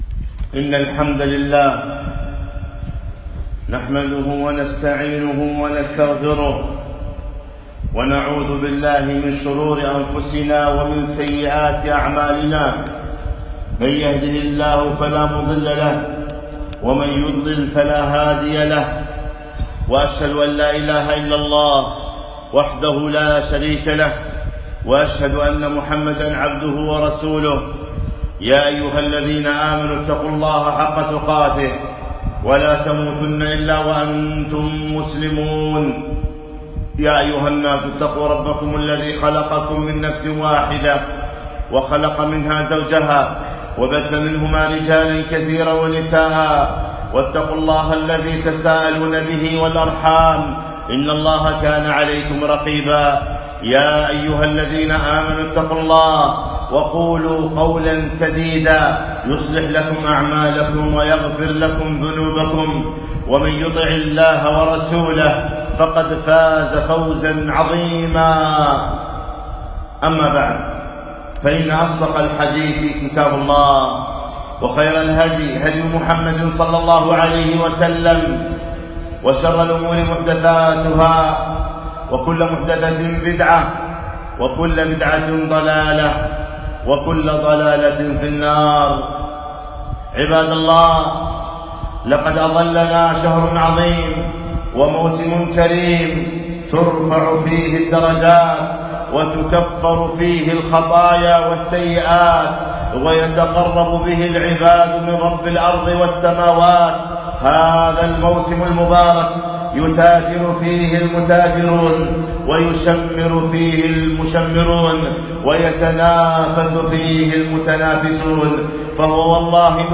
خطبة - تعرضوا لنفحات رمضان